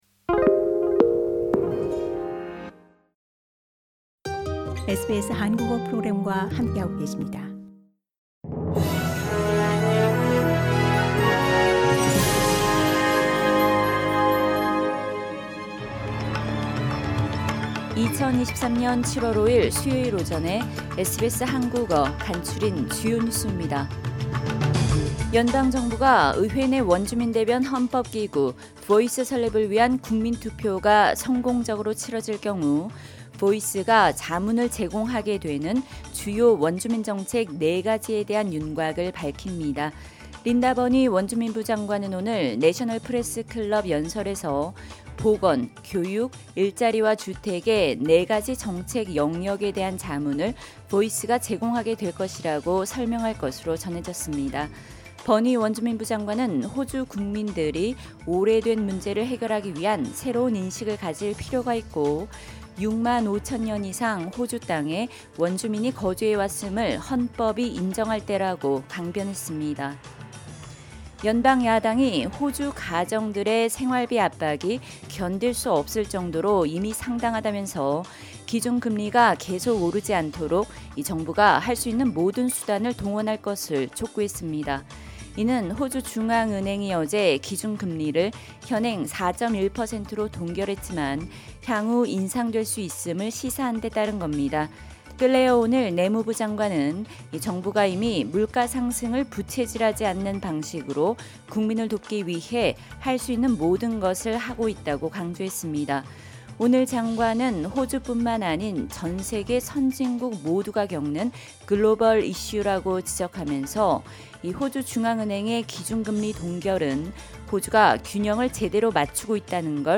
SBS 한국어 아침 뉴스: 2023년 7월 5일 수요일